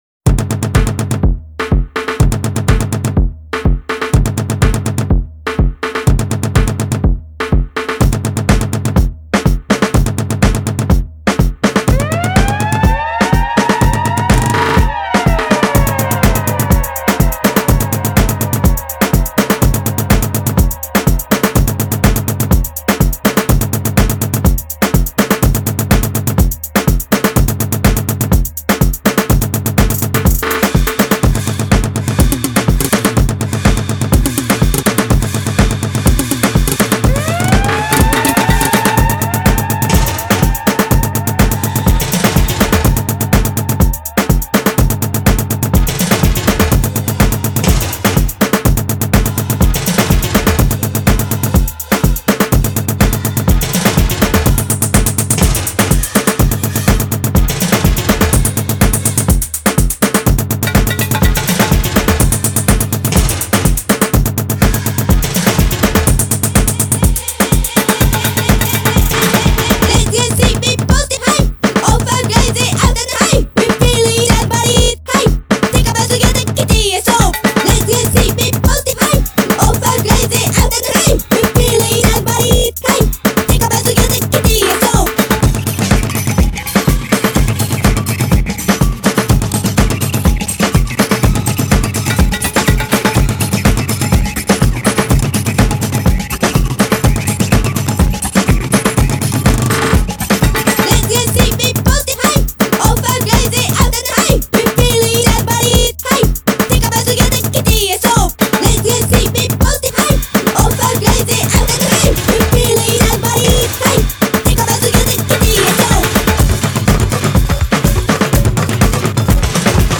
the drums
Listen to these, well BANGING.